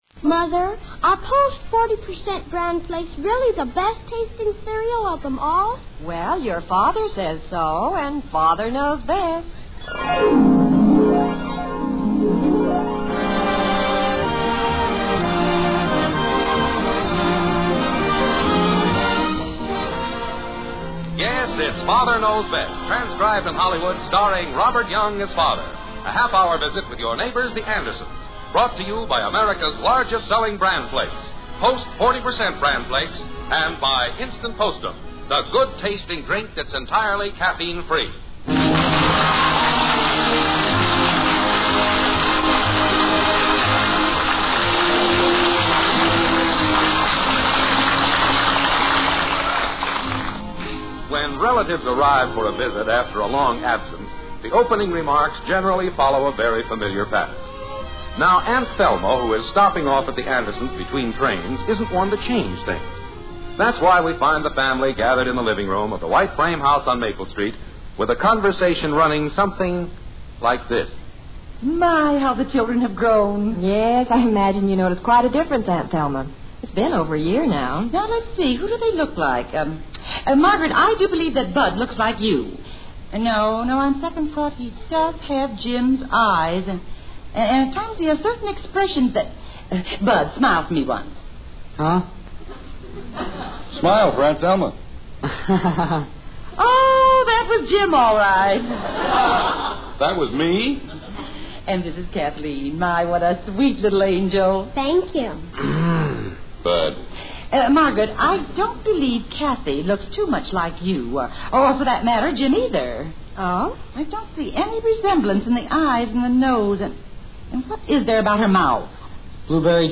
The Father Knows Best Radio Program